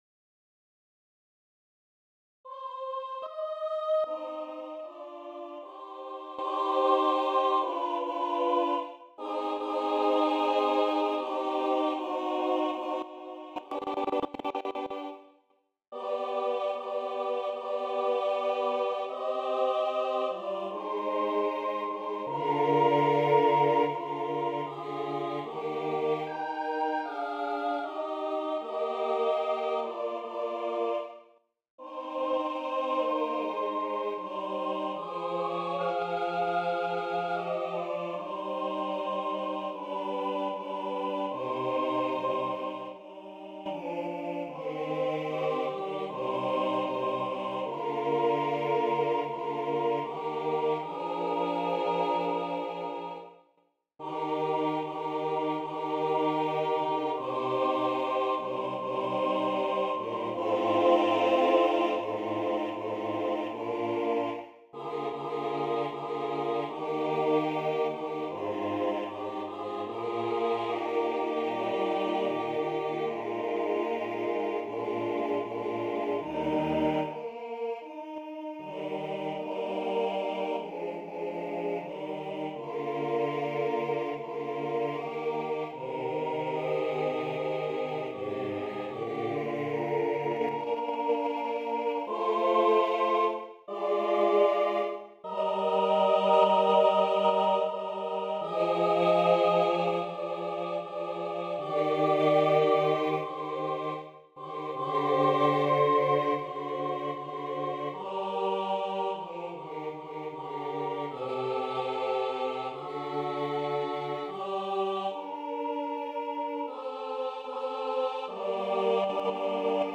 Концерт для смешанного хора и солистов, a cappella